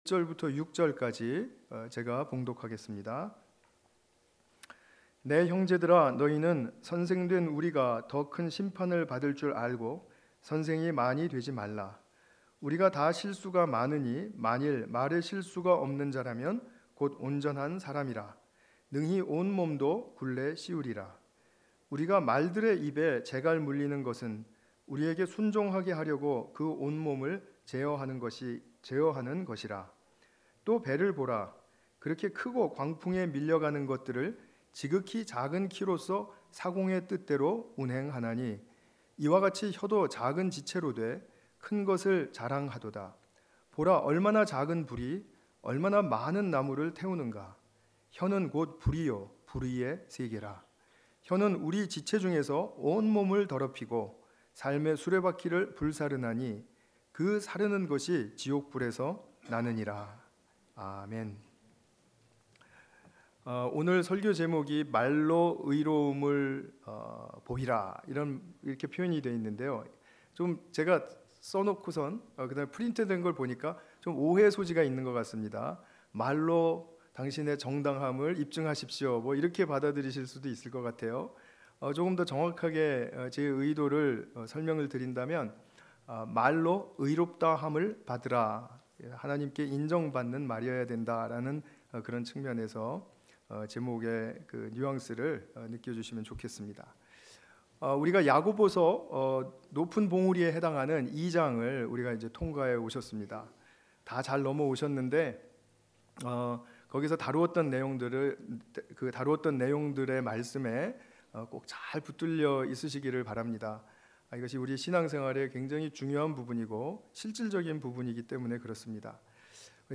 오직 믿음으로 구하고 조금도 의심하지 말라 의심하는 자는 마치 바람에 밀려 요동하는 바다 물결 같으니 관련 Tagged with 주일예배 Audio (MP3) 65 MB 이전 만유의 상속자, 예수 그리스도 다음 야고보서 (12) - 찬송의 말과 저주의 말 0 댓글 댓글 추가 취소 댓글을 달기 위해서는 로그인 해야합니다.